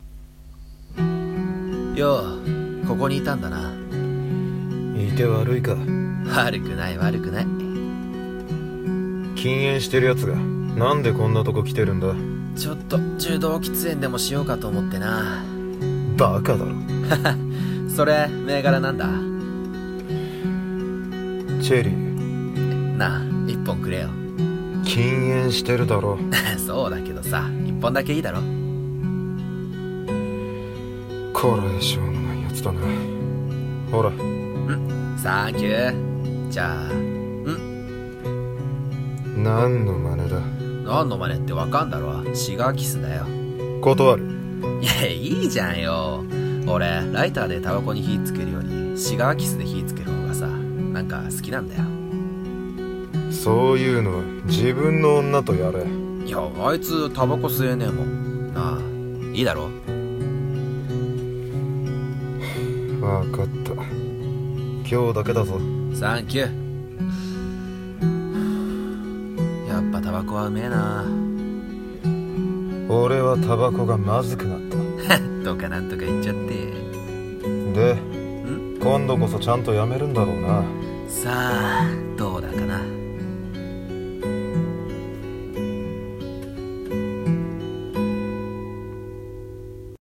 【声劇】煙草【掛け合い】